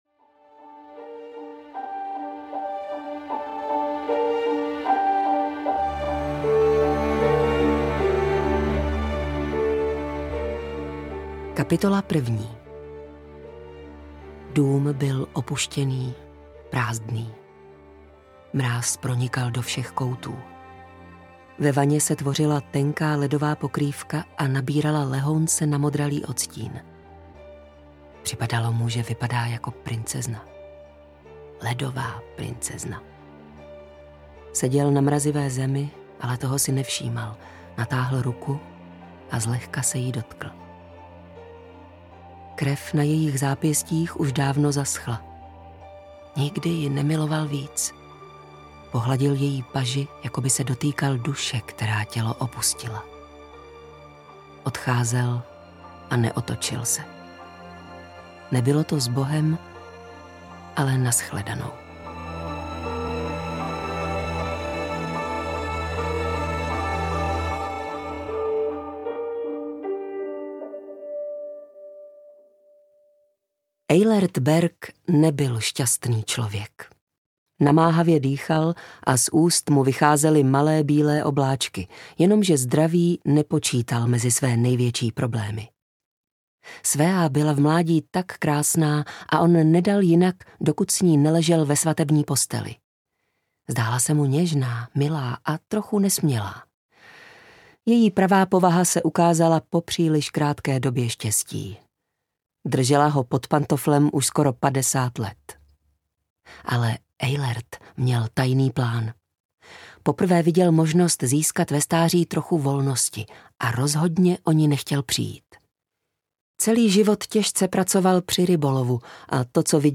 Ledová princezna audiokniha
Ukázka z knihy